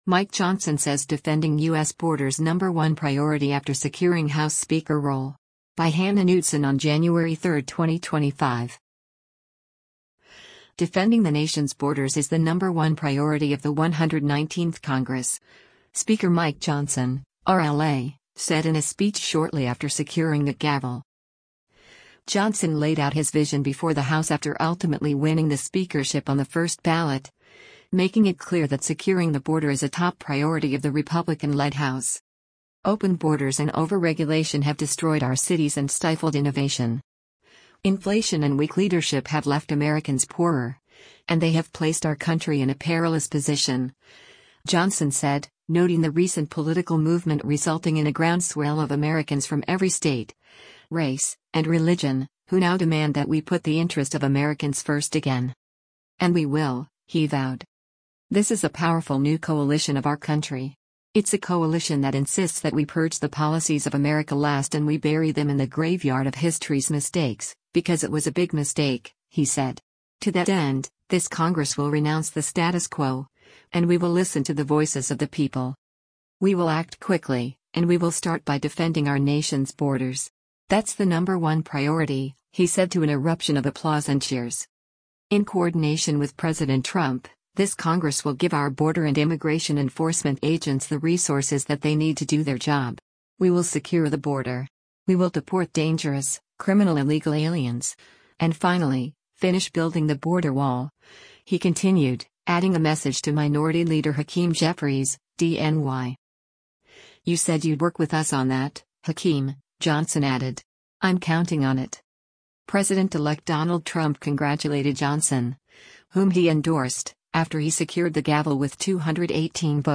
Defending the nation’s borders is the “number one priority” of the 119th Congress, Speaker Mike Johnson (R-LA) said in a speech shortly after securing the gavel.
Johnson laid out his vision before the House after ultimately winning the speakership on the first ballot, making it clear that securing the border is a top priority of the Republican-led House.
“We will act quickly, and we will start by defending our nation’s borders. That’s the number one priority,” he said to an eruption of applause and cheers.